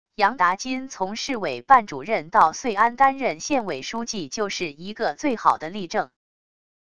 杨达金从市委办主任到遂安担任县委书记就是一个最好的例证wav音频生成系统WAV Audio Player